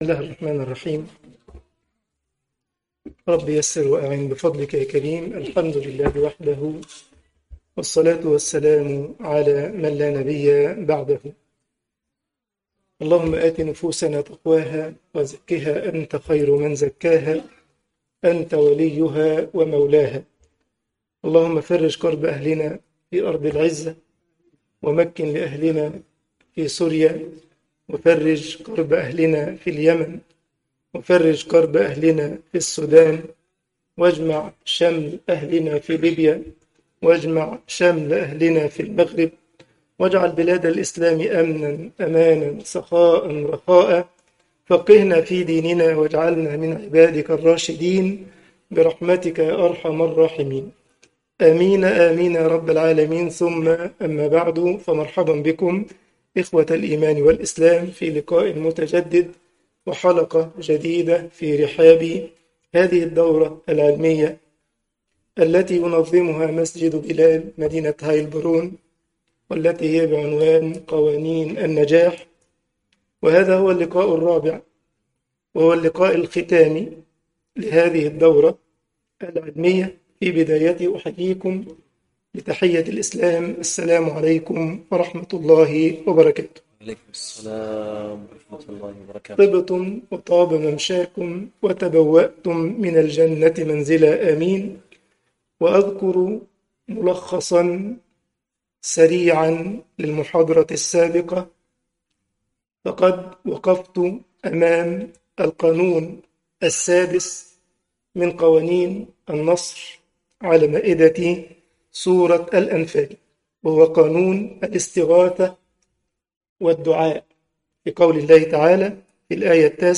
المحاضرة 4